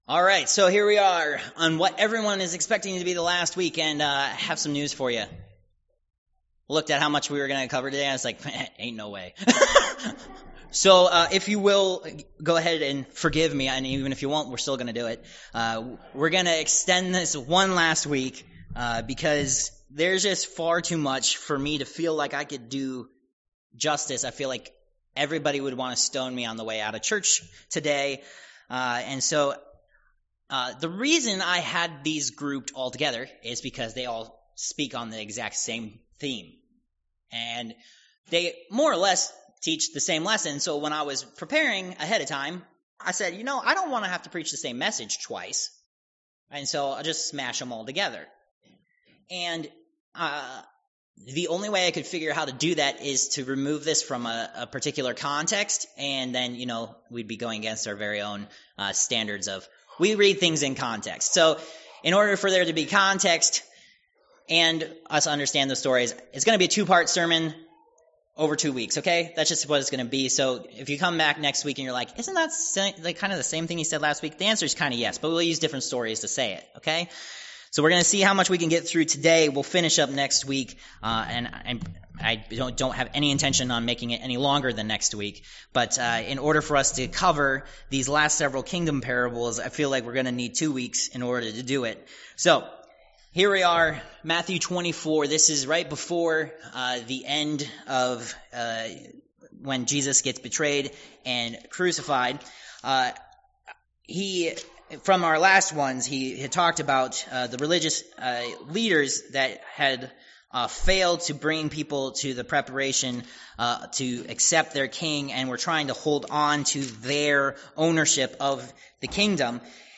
Matt. 24:32-44 Service Type: Worship Service « Protected